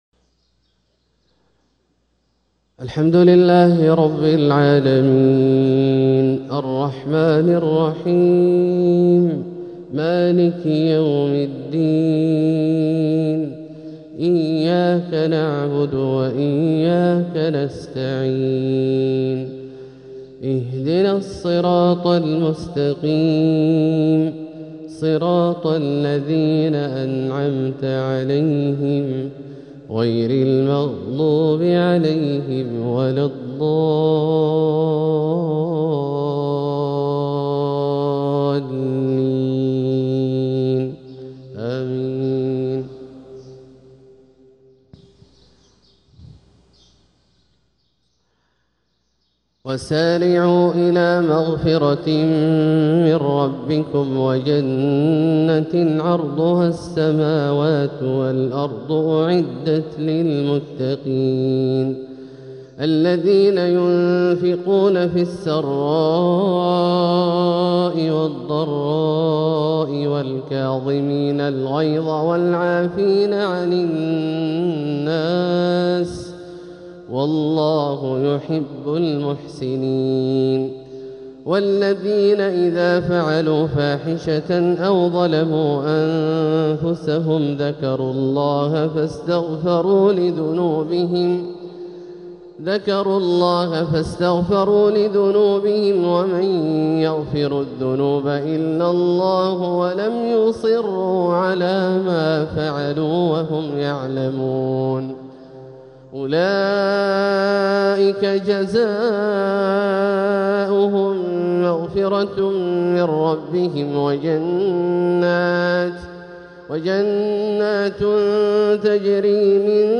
فجرية نديّة من سورة آل عمران 133-152 | 2-6-1447هـ > ١٤٤٧هـ > الفروض - تلاوات عبدالله الجهني